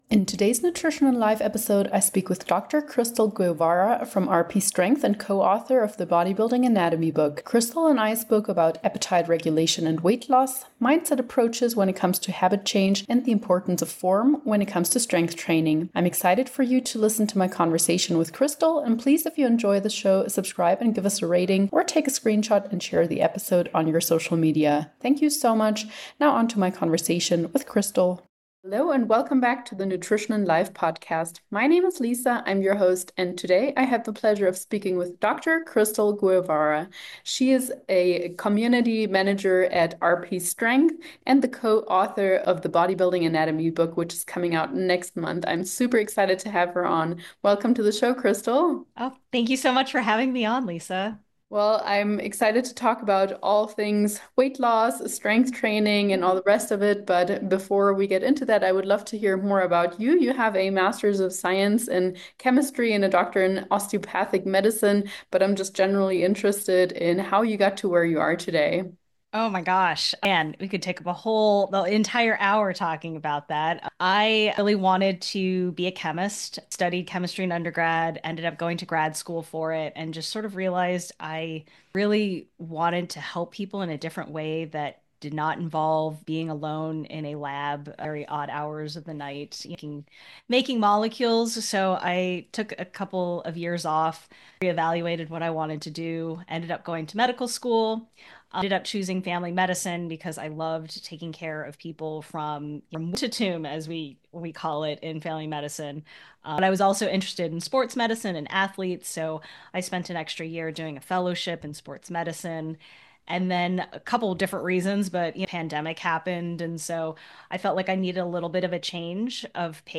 So, sit back, relax, and get ready for a conversation packed with expert advice and inspiring stories aimed at transforming your approach to health and fitness!